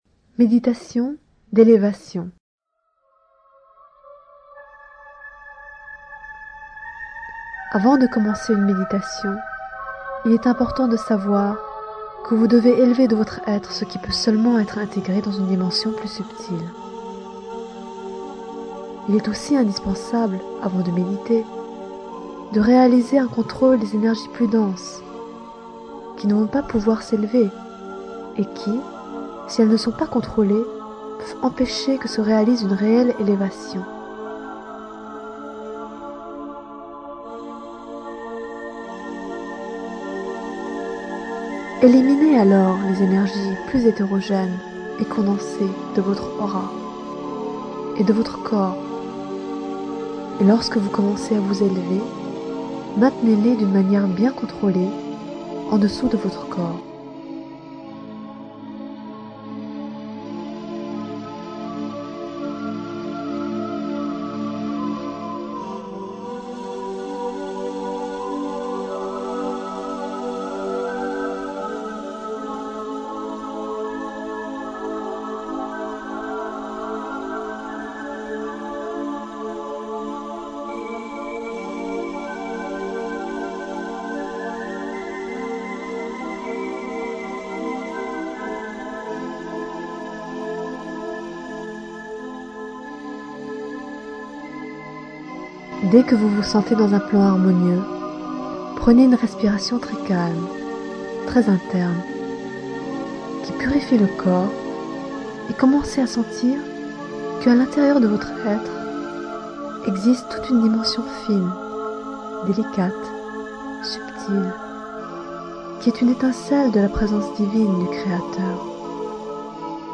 Méditation d'élévation (audio)
meditation_elevation.mp3